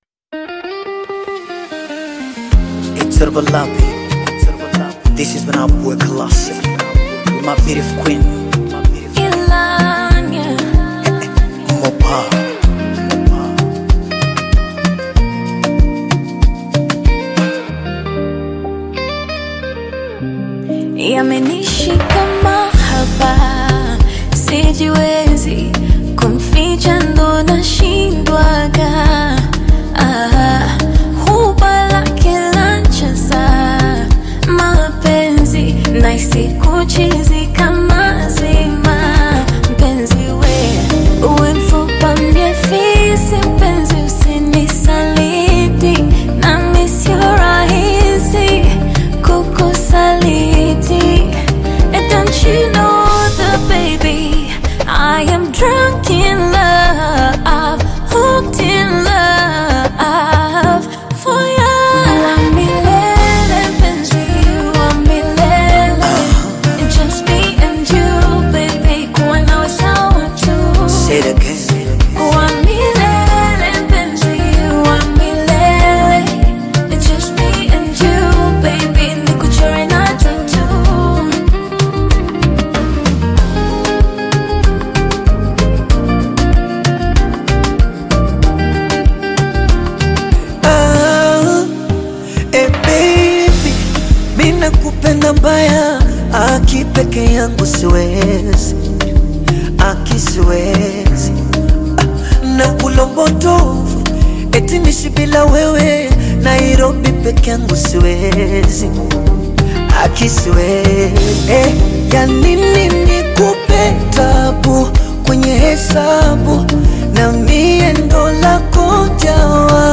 soulful new single
delivers heartfelt melodies
adds his signature smooth vocals and emotional depth.